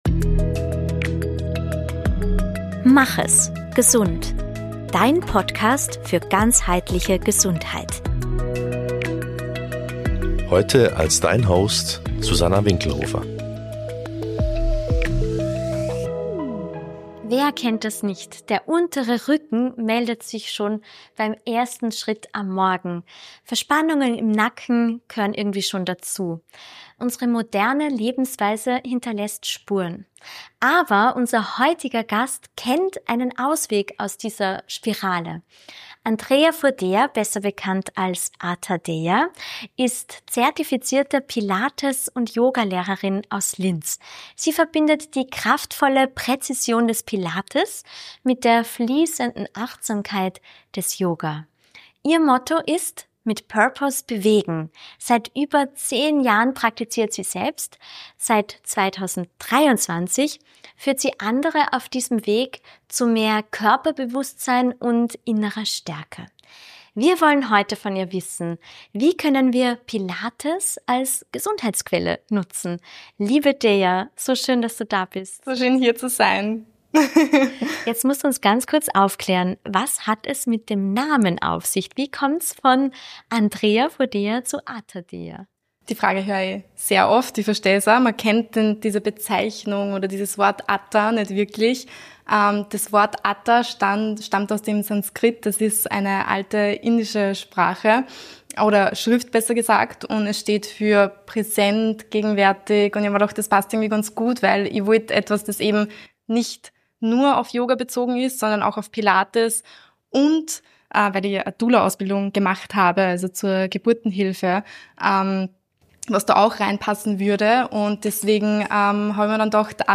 Ein Gespräch über Stabilität von innen.